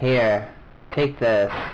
msx dialogue
msx_here_take_this.wav